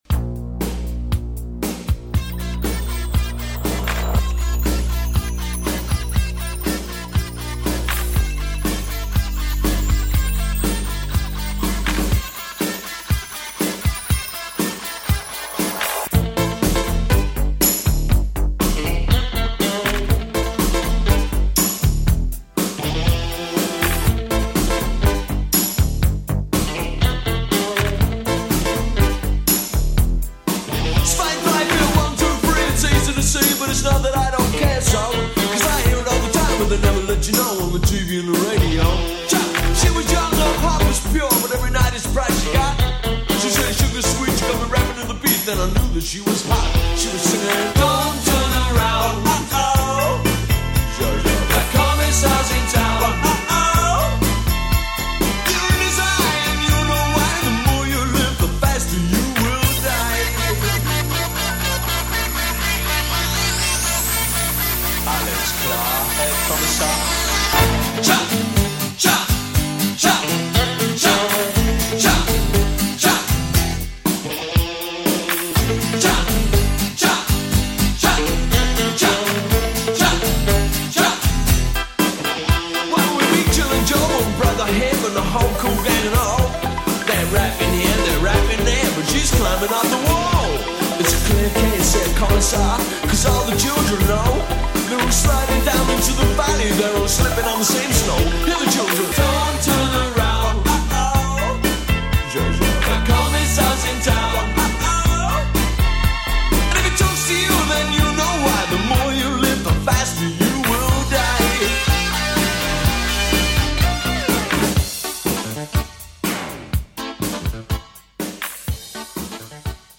BPM108-129
Audio QualityMusic Cut